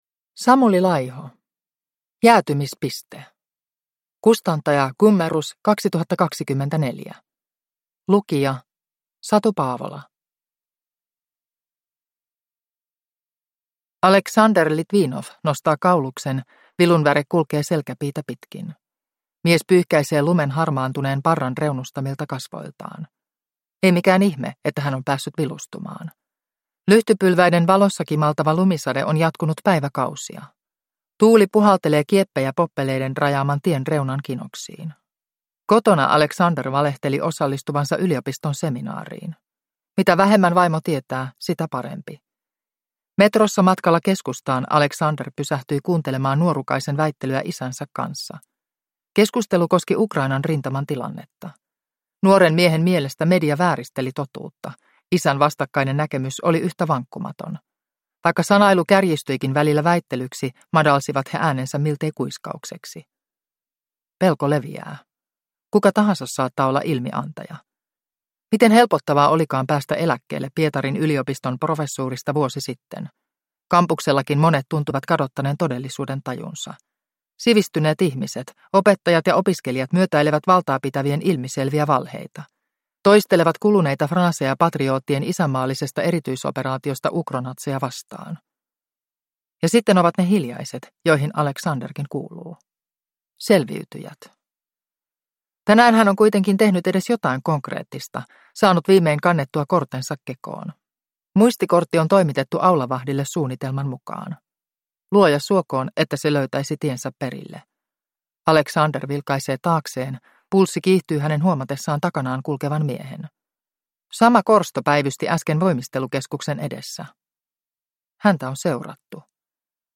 Jäätymispiste (ljudbok) av Samuli Laiho